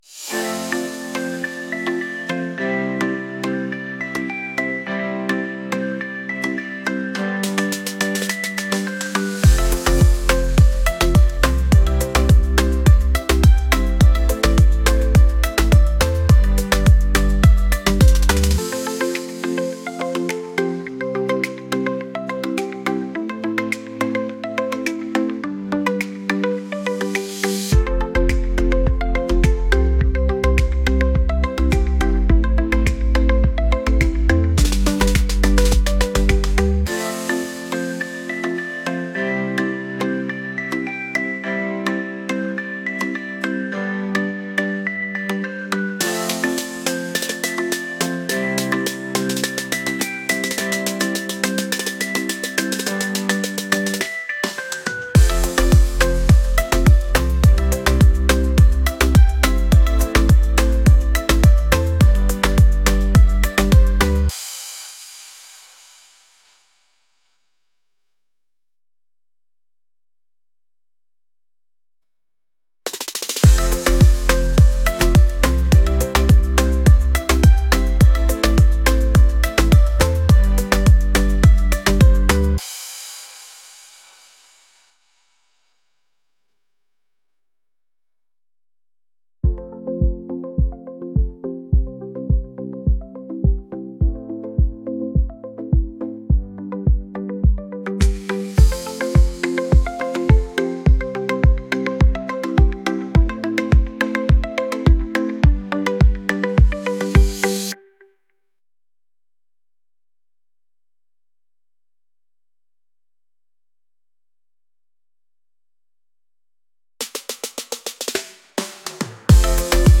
upbeat | pop